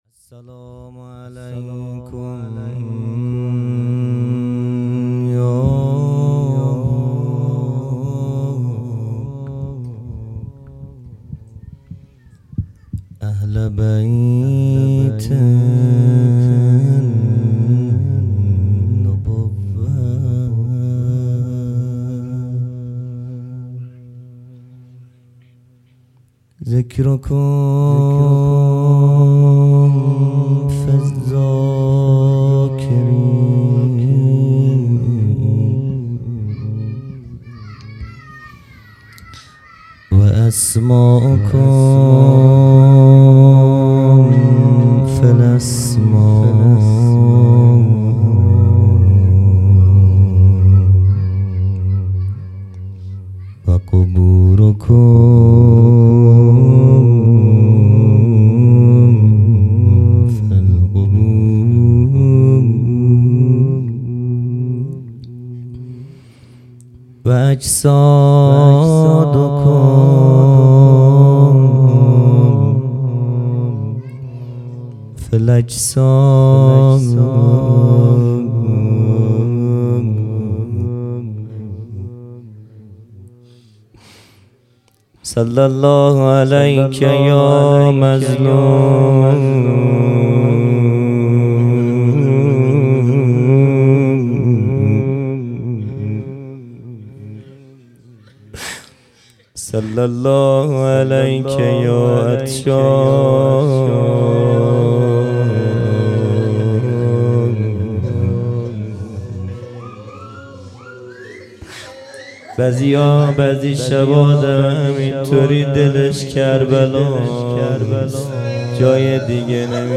خیمه گاه - هیئت بچه های فاطمه (س) - روضه